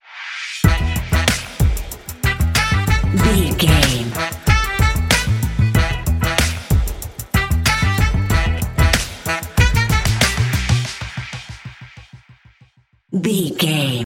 Ionian/Major
hip hop
saxophone
drum machine
groovy
energetic